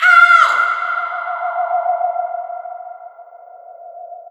AAHH VOX 1-L.wav